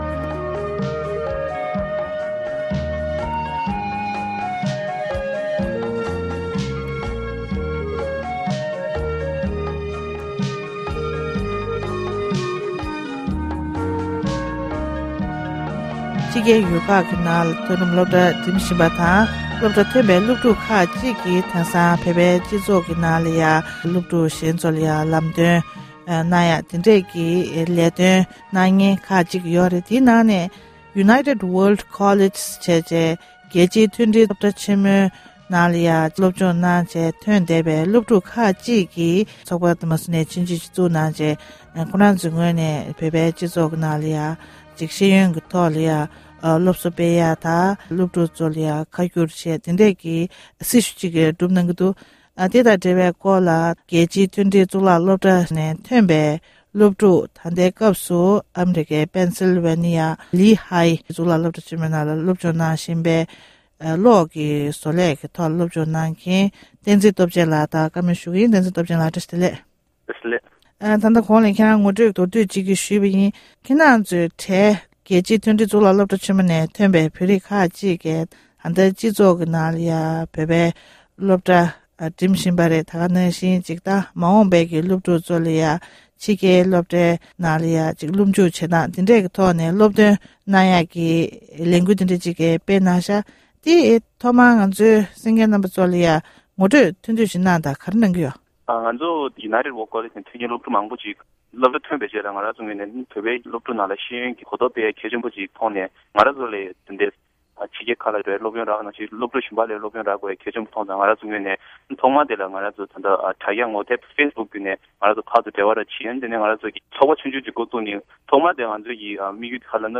སྒྲ་ལྡན་གསར་འགྱུར། སྒྲ་ཕབ་ལེན།
གནས་འདྲི